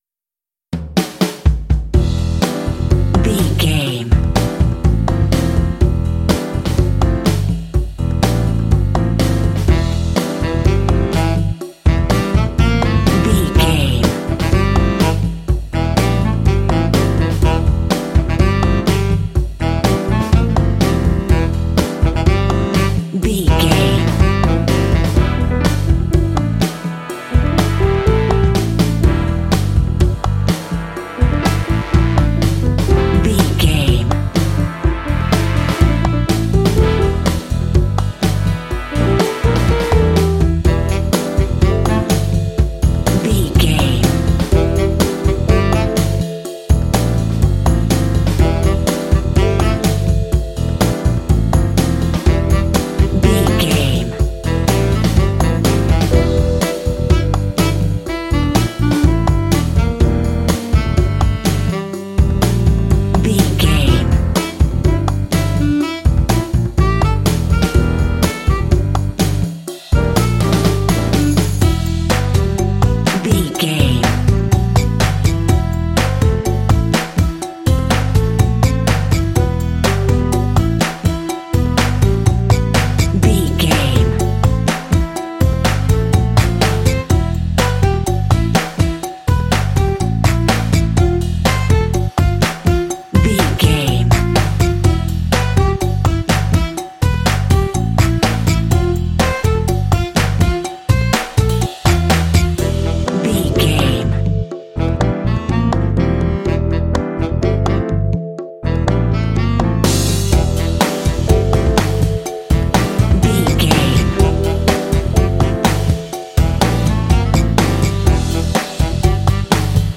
Phrygian
funky
happy
bouncy
groovy
piano
drums
saxophone
bass guitar
brass
percussion
latin
jazz
Funk